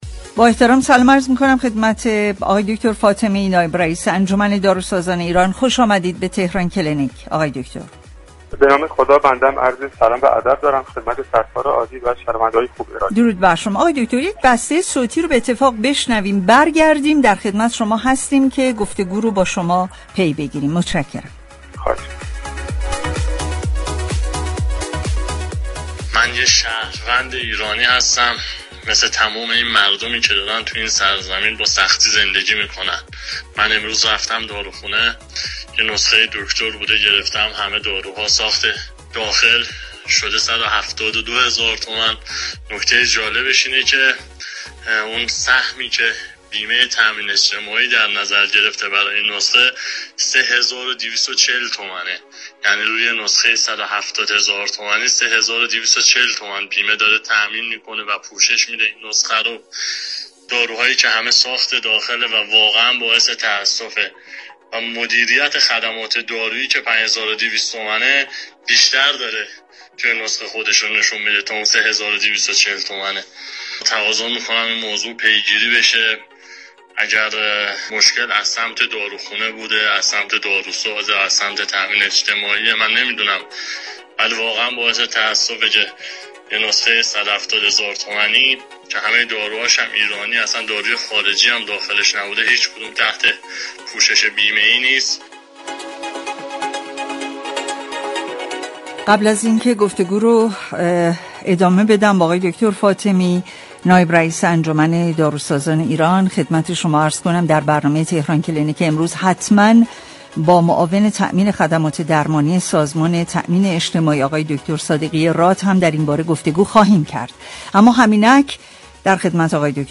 در گفت‌وگو با تهران كلینیك رادیو تهران